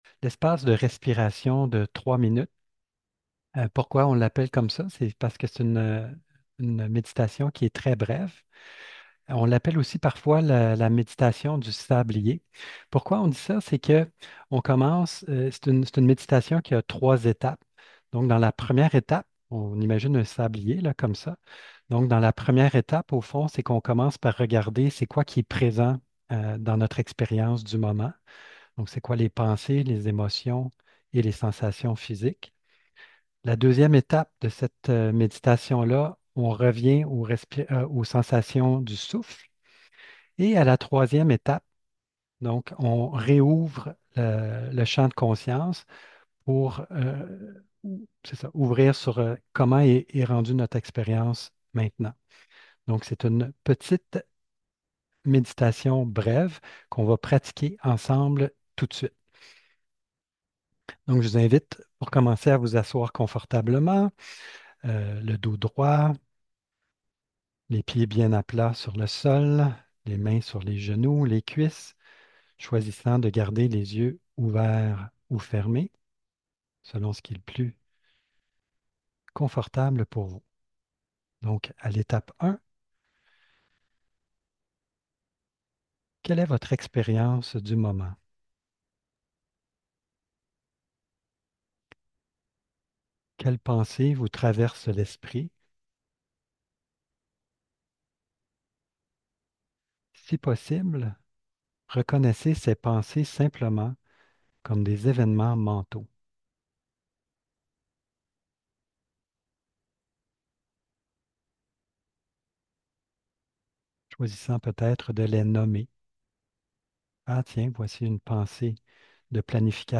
La mini-méditation